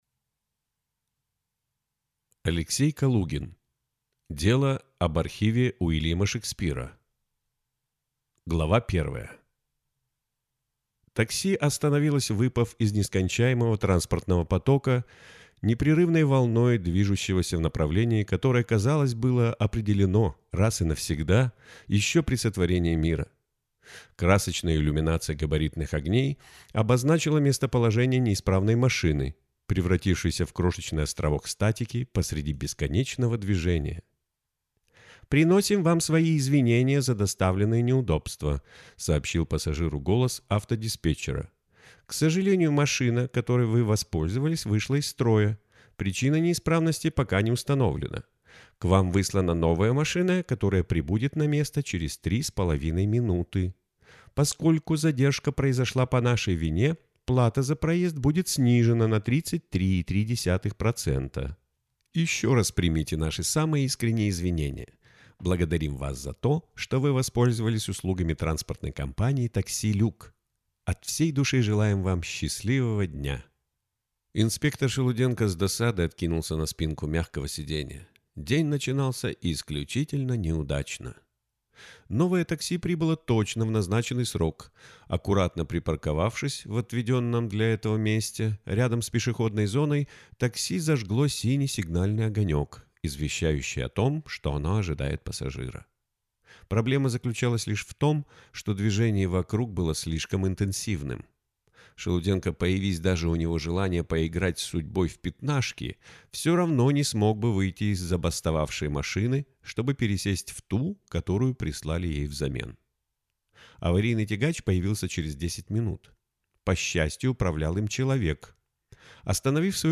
Аудиокнига Дело об архиве Уильяма Шекспира | Библиотека аудиокниг
Прослушать и бесплатно скачать фрагмент аудиокниги